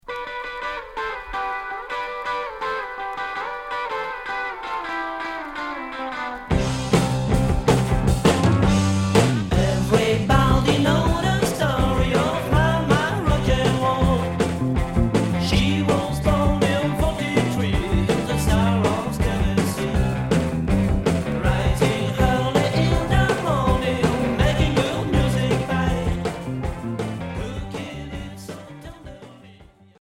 Rock et groove